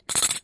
冲击、撞击、摩擦 工具 " 轻金属响声很短
Tag: 工具 工具 崩溃 砰的一声 塑料 摩擦 金属 冲击